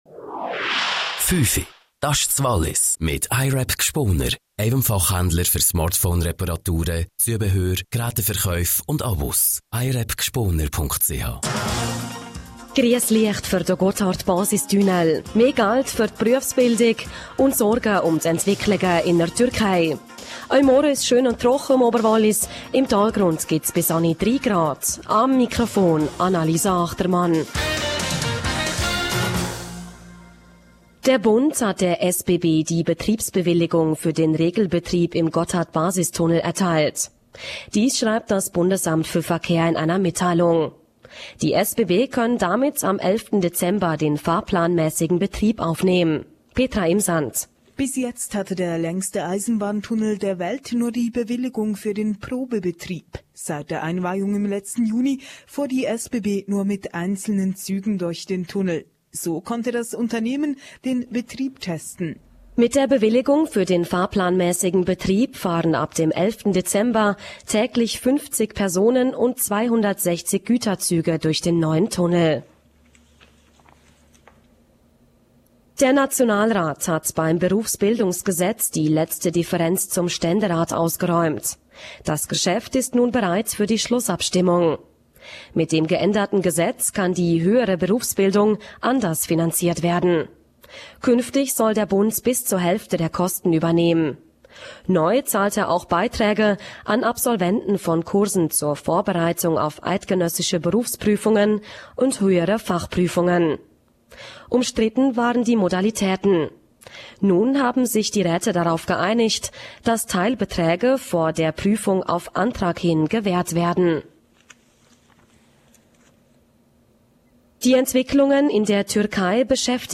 17:00 Uhr Nachrichten (4.53MB)